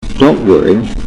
英語音声 アクセントがある場合 16_M 20_M 21_M
k_worry.mp3